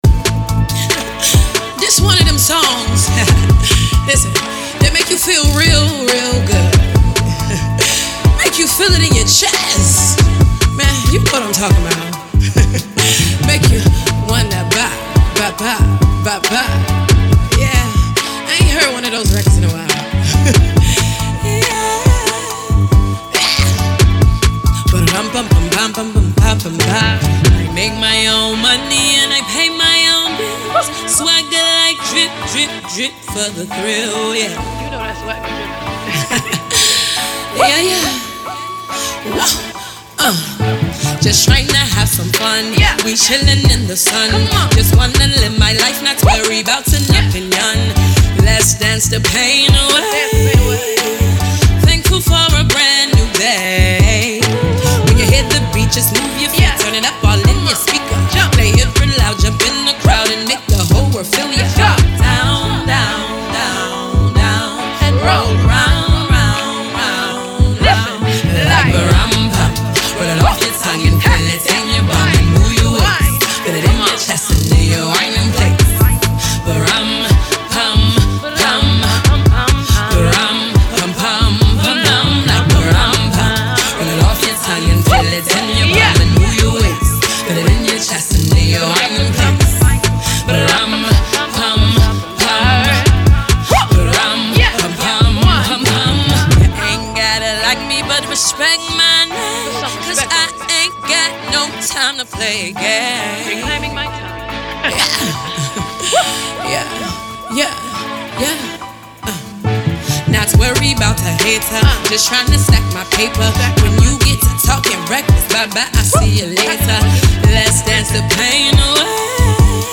Rap
Another positive, bouncy, motivational single